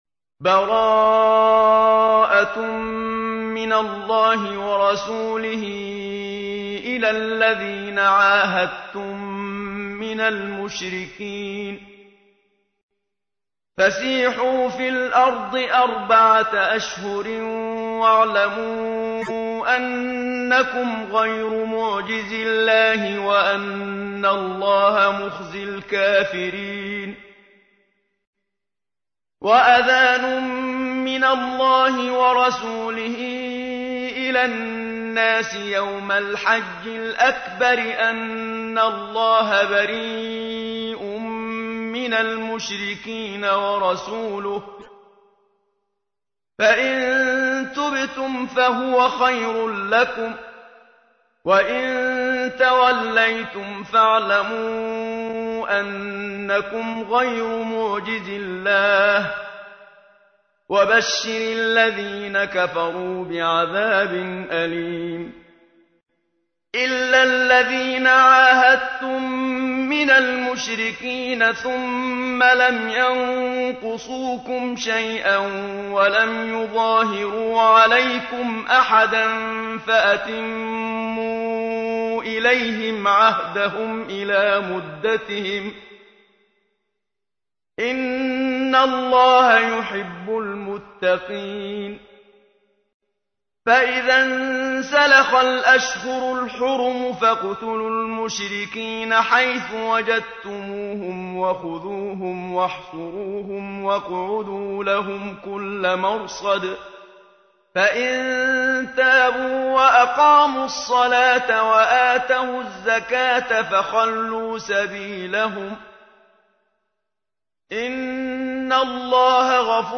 تحميل : 9. سورة التوبة / القارئ محمد صديق المنشاوي / القرآن الكريم / موقع يا حسين